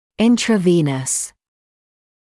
[ˌɪntrə’viːnəs][ˌинтрэ’виːнэс]внутривенный